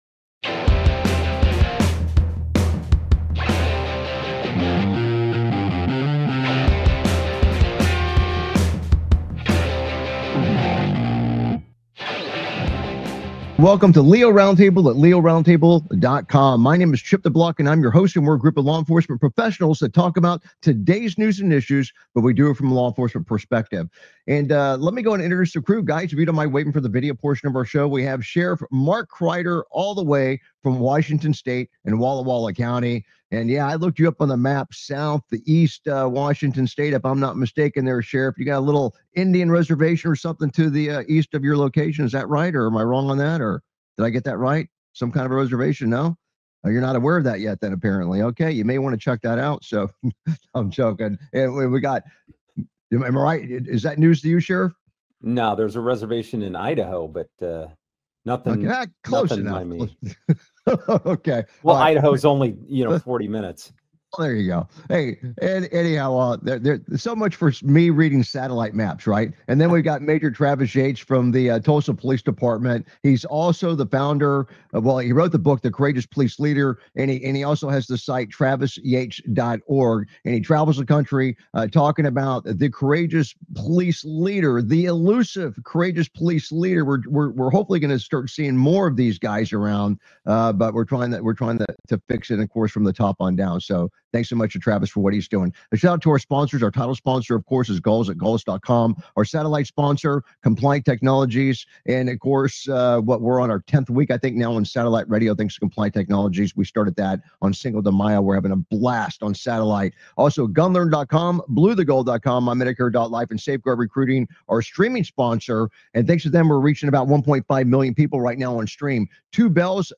LEO Round Table is a nationally syndicated law enforcement satellite radio talk show discussing today's news and issues from a law enforcement perspective.
Their panelists are among a Who's Who of law enforcement professionals and attorneys from around the country.